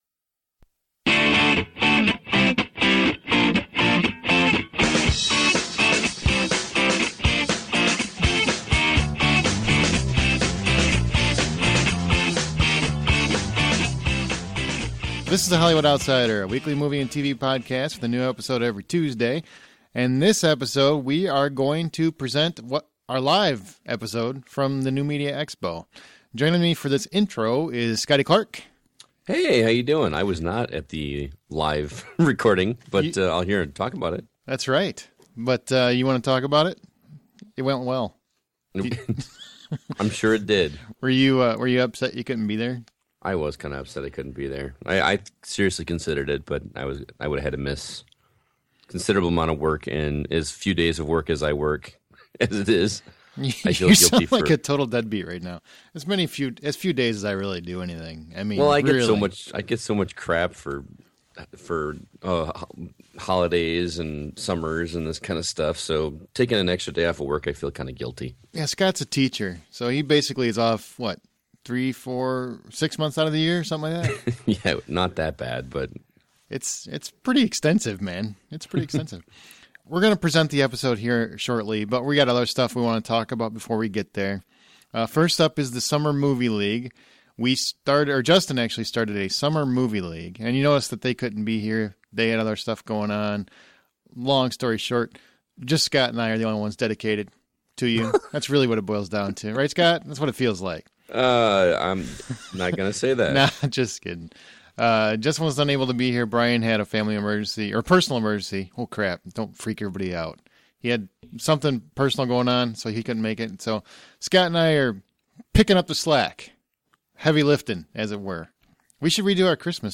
Live From New Media Expo